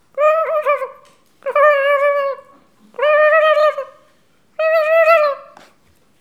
bruit-animal_25.wav